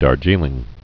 (där-jēlĭng)